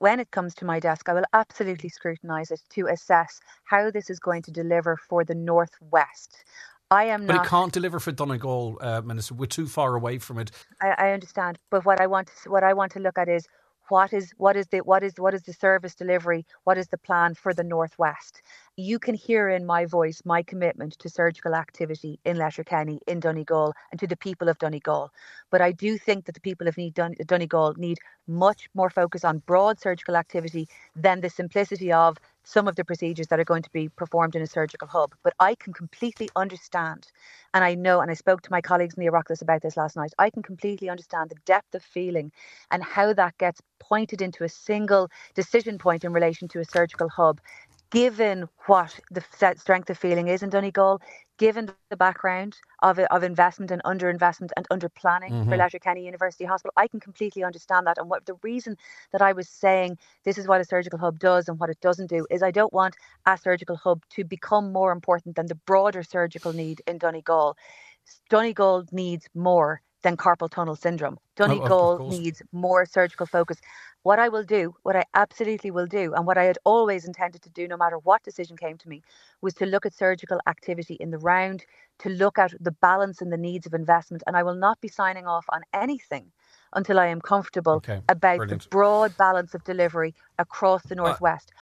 The Minister is yet to receive a business case for Letterkenny University Hospital, but says she will scrutinise it: